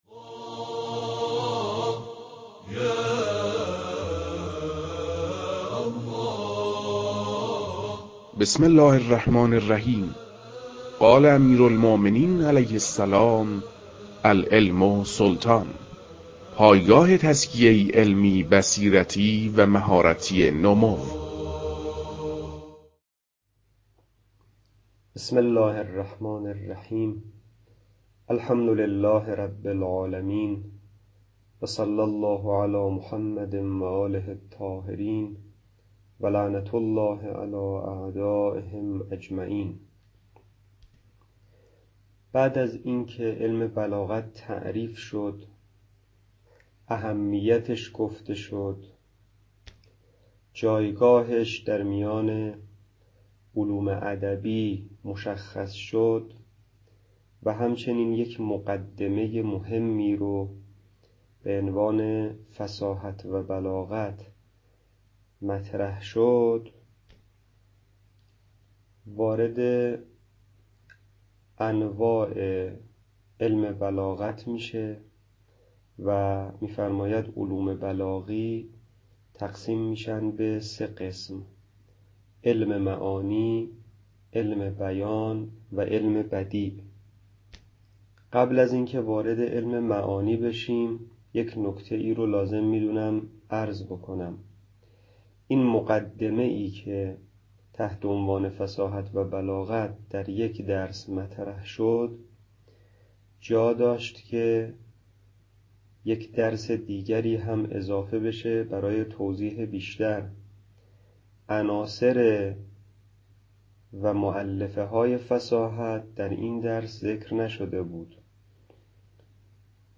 در این بخش، کتاب «لمحات من البلاغة» که اولین کتاب در مرحلۀ آشنایی با علم بلاغت است، به صورت ترتیب مباحث کتاب، تدریس می‌شود.
در تدریس این کتاب- با توجه به سطح آشنایی کتاب- سعی شده است، مطالب به صورت روان و در حد آشنایی ارائه شود.